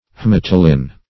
Haematolin \H[ae]*mat"o*lin\ (-l[i^]n)